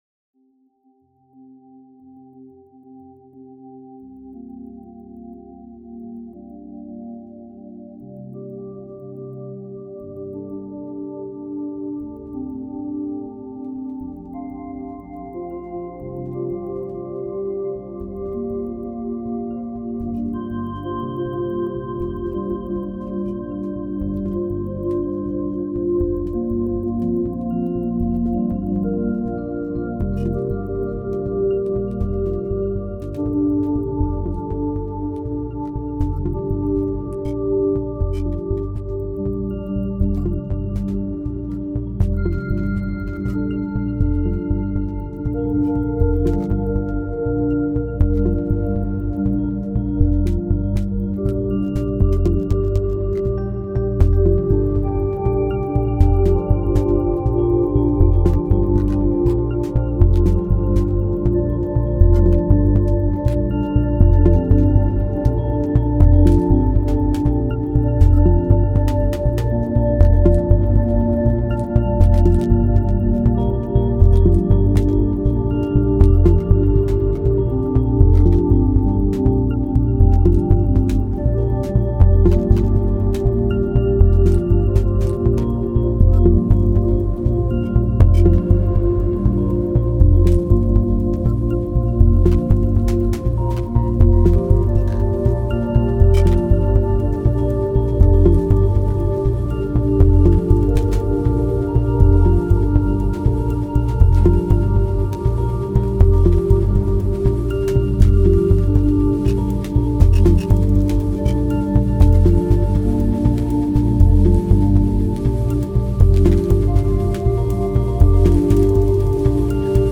Genre: Dub Techno/Ambient.